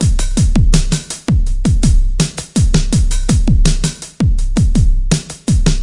工业鼓循环 1
描述：82bpm 2 bar industrial drum loop